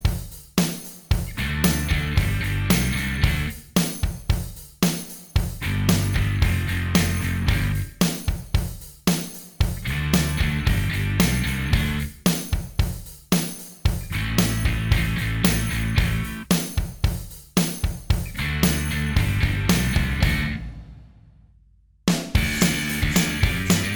Minus Lead Guitar Punk 3:11 Buy £1.50